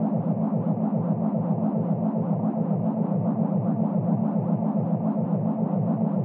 sci-fi_forcefield_hum_loop_02.wav